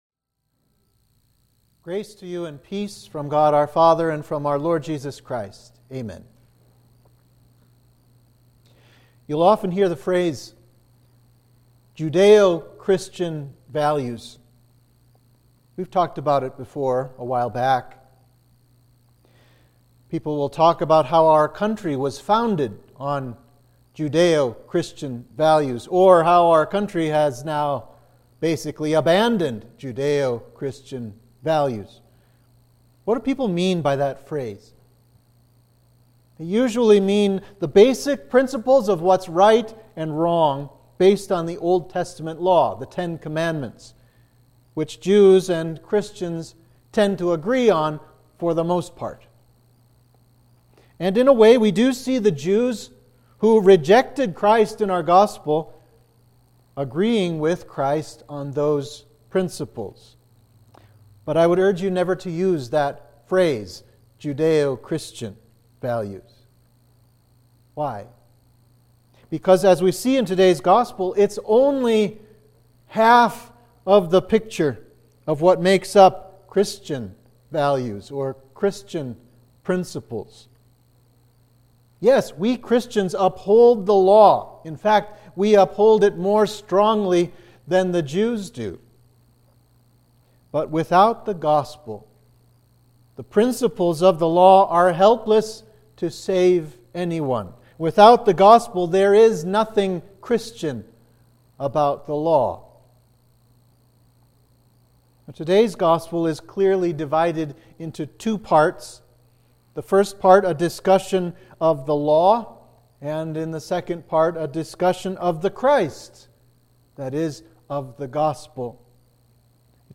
Sermon for Trinity 18